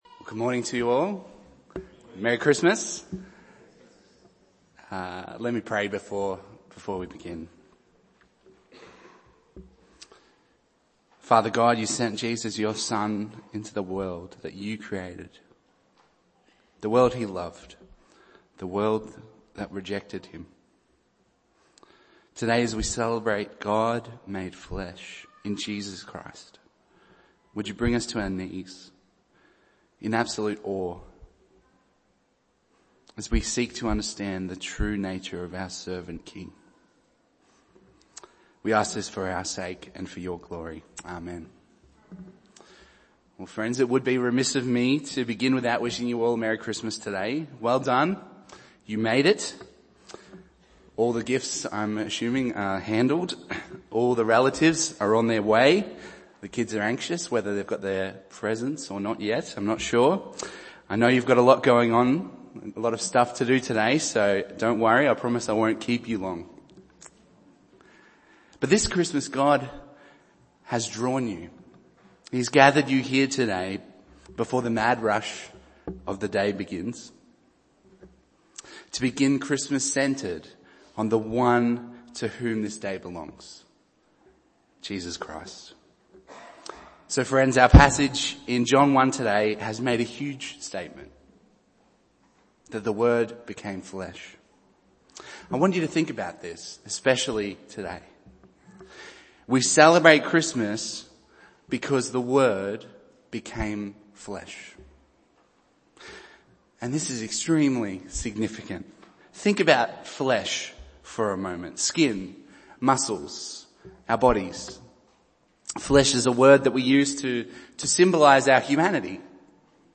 Series: Christmas 2017